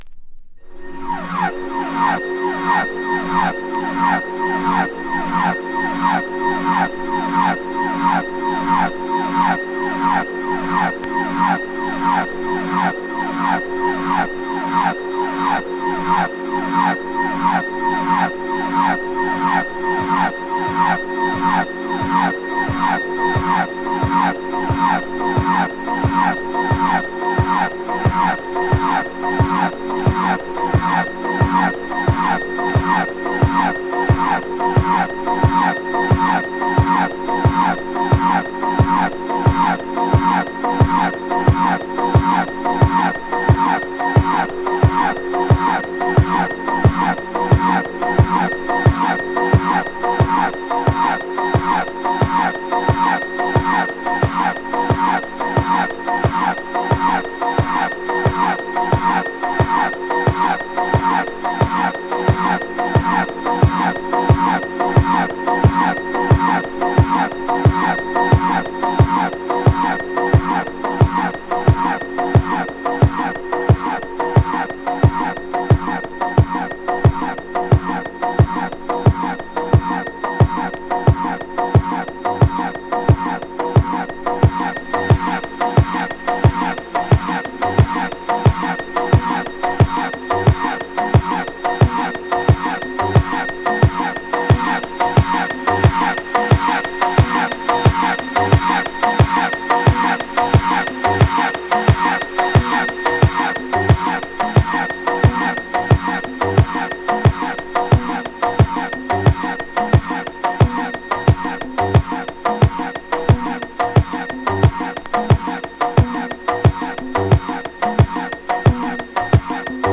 B面も同様、メランコリックなヴァイヴが美しい傑作！